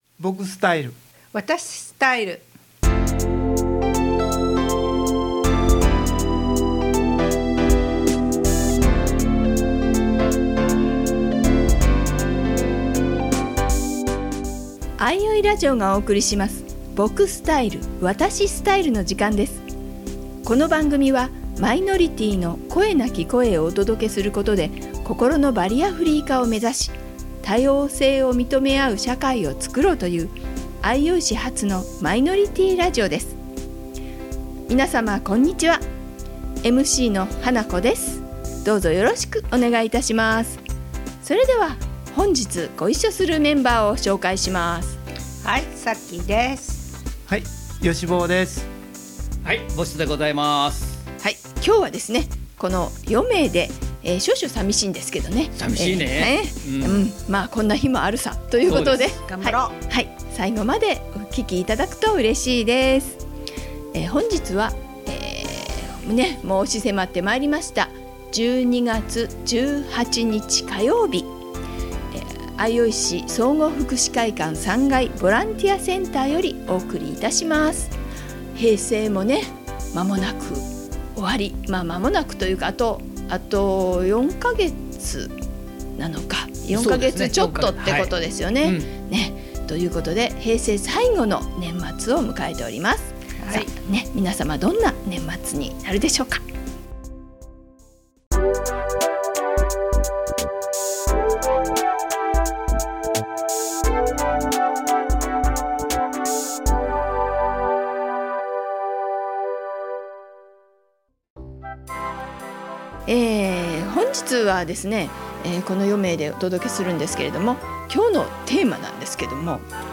場所：相生市総合福祉会館